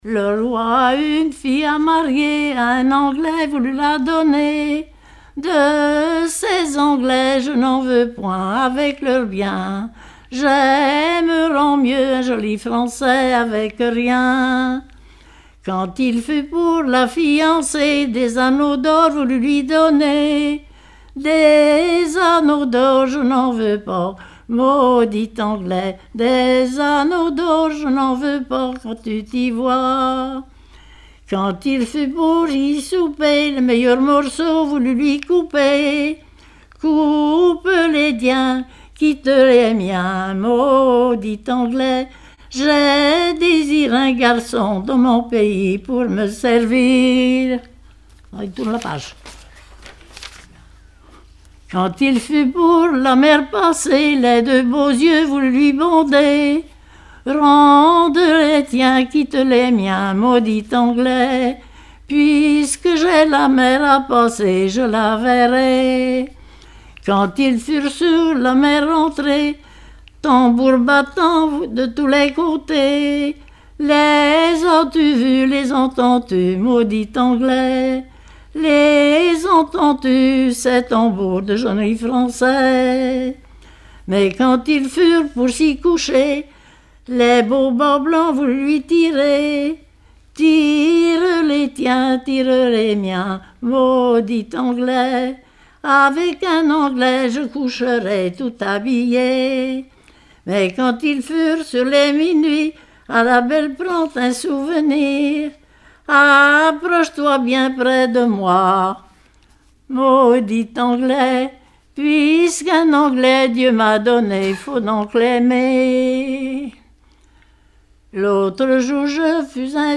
Genre strophique
enregistrement d'un collectif lors d'un regroupement cantonal
Pièce musicale inédite